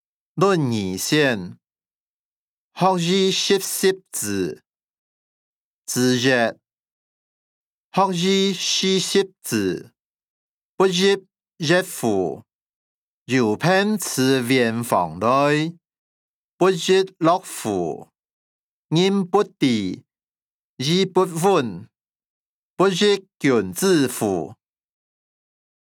經學、論孟-論語選．學而時習之音檔(饒平腔)